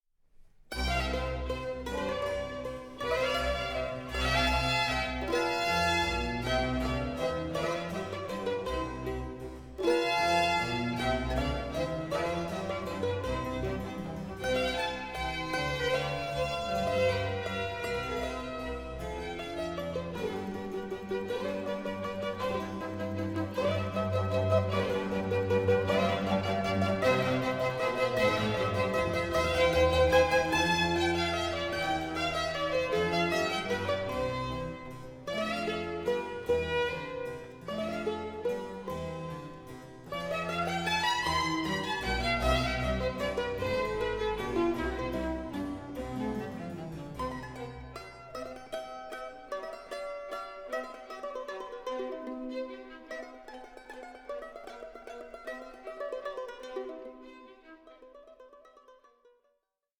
works for mandolin and orchestra